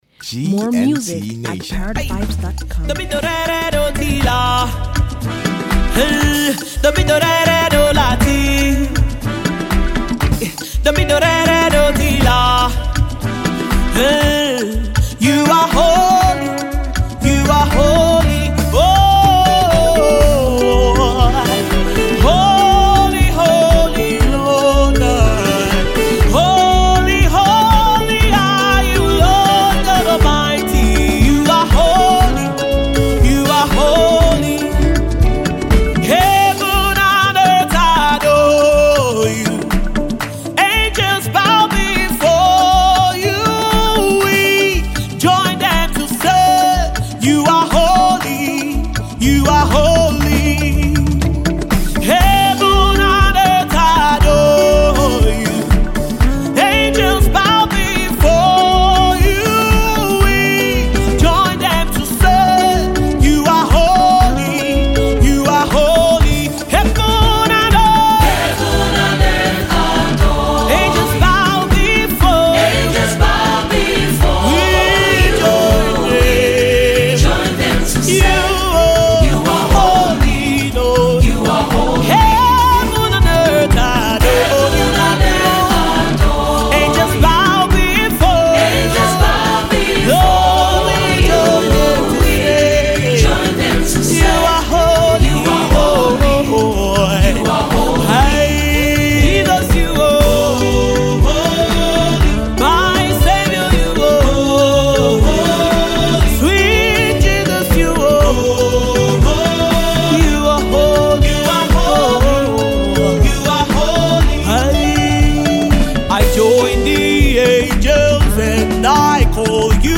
Remarkably talented Nigerian gospel vocalist
GOSPEL